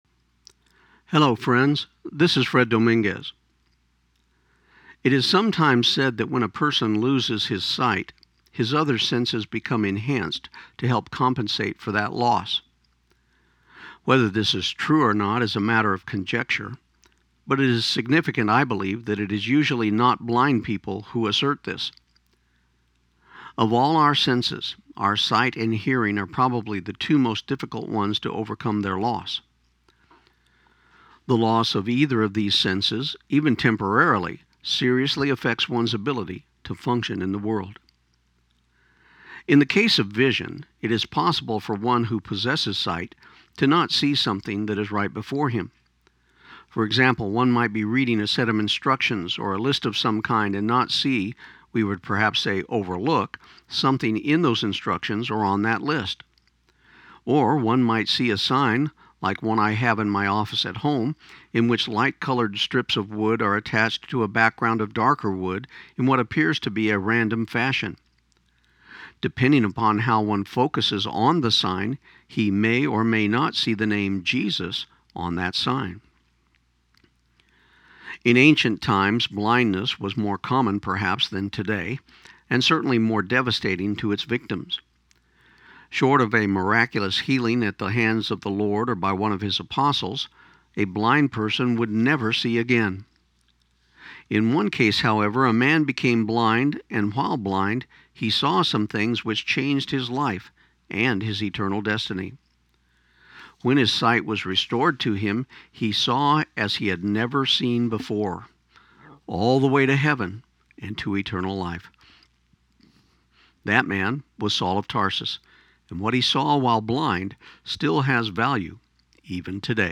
This program aired on KIUN 1400 AM in Pecos, TX on June 17, 2016.